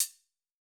Index of /musicradar/ultimate-hihat-samples/Hits/ElectroHat C
UHH_ElectroHatC_Hit-24.wav